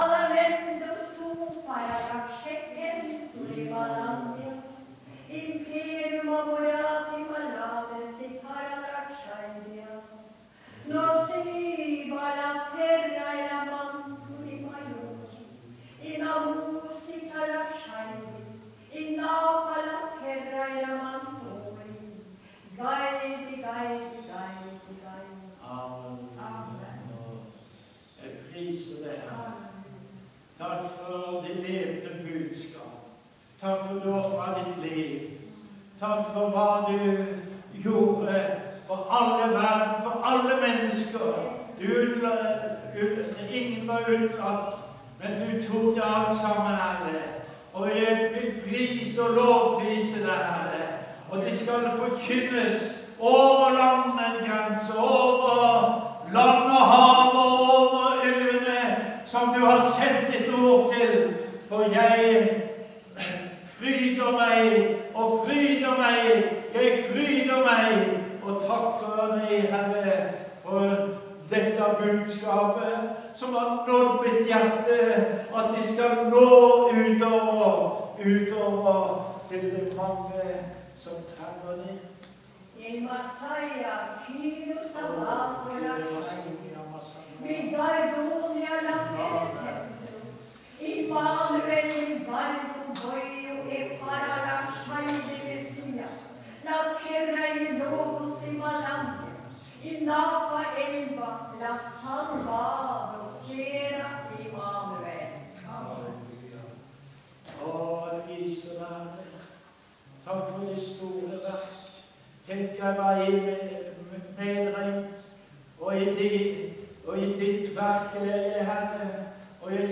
Møte i Maranata 29.4.
Tyding av tungetale.